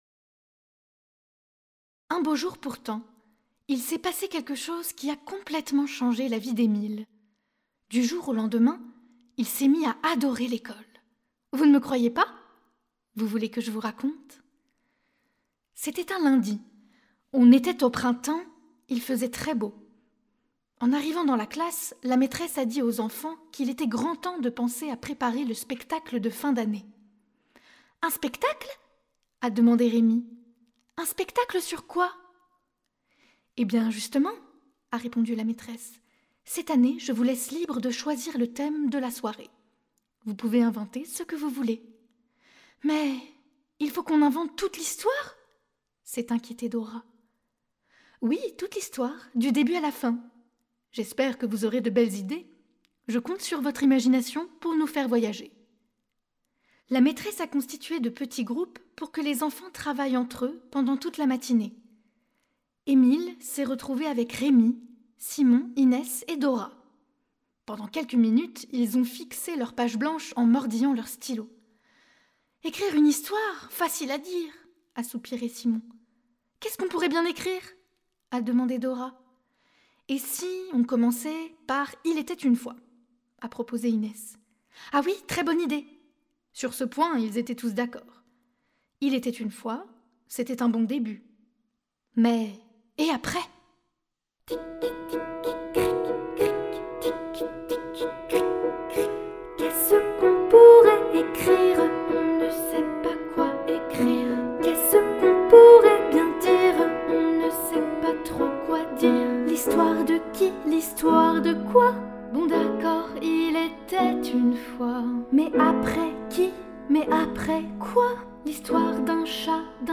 Genre :  ChansonComptine
Effectif :  UnissonVoix égales
Audio Voix et Piano